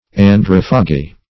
Search Result for " androphagi" : The Collaborative International Dictionary of English v.0.48: Androphagi \An*droph"a*gi\ ([a^]n*dr[o^]f"[.a]*j[i^]), n. pl.